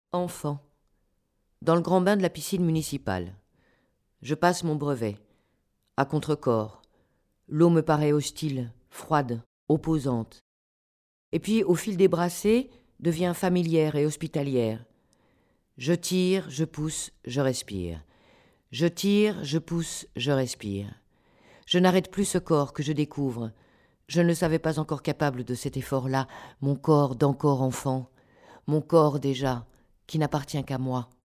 grave